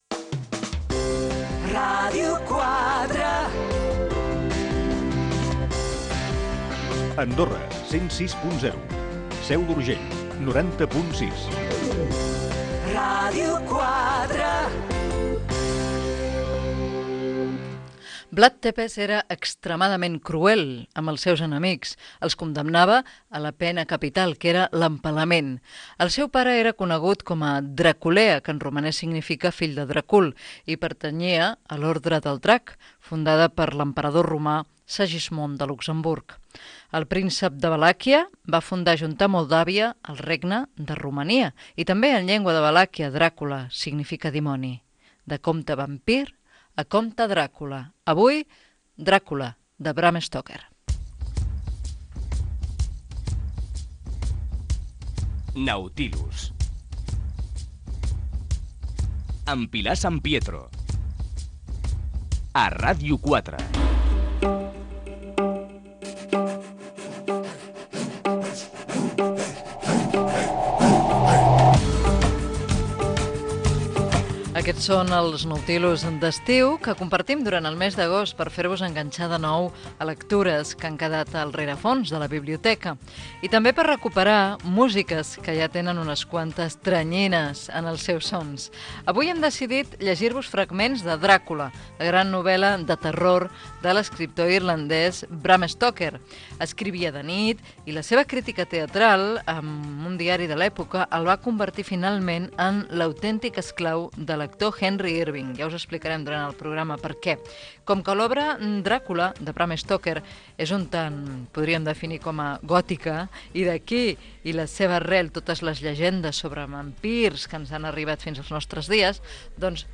Indicatiu de la ràdio amb algunes de les freqüències, introducció de l'espai dedicat a la lectura de "Dràcula" de Bram Stoke , indicatiu del programa, comentari lectura del primer fragment de l'obra, tema musical, comentari del grup musical que s'ha escoltat, lectura del segon fragment i tema musical
FM